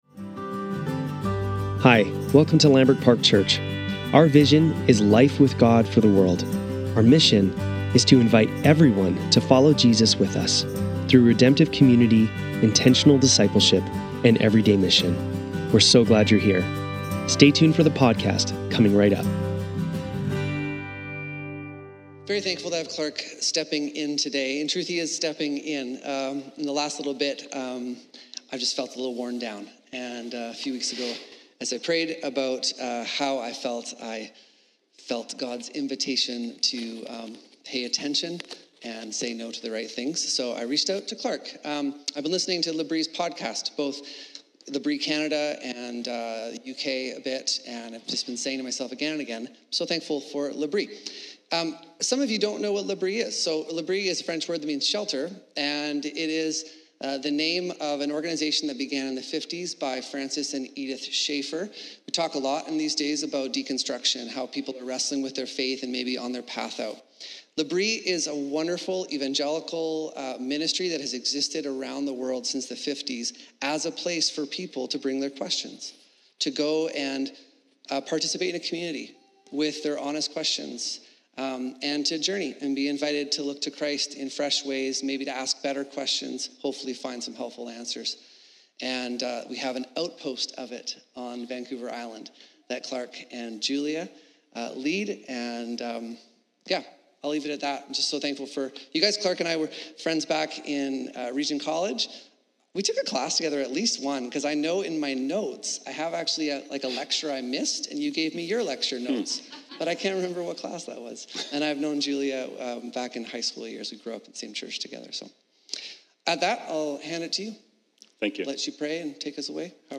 Lambrick Sermons | Lambrick Park Church
March 13, 2022 - Sunday Service